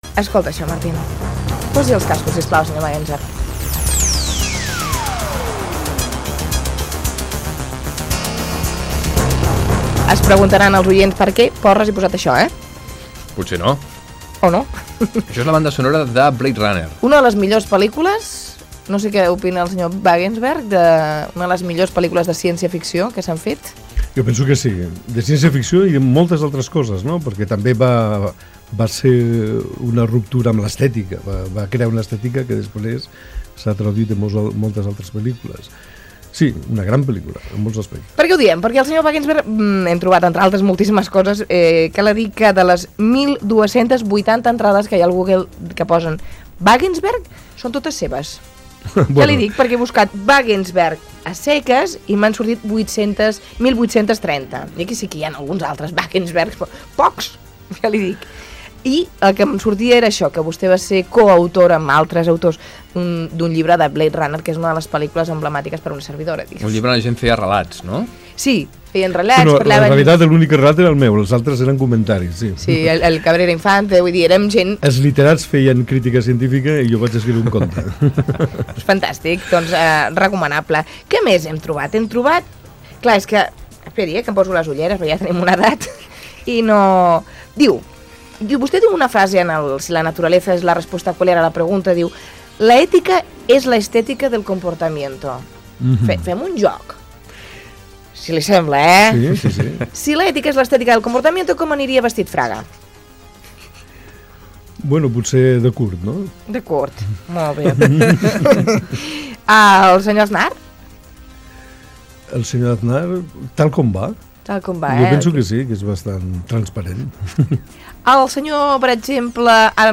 Fragment d'una entrevista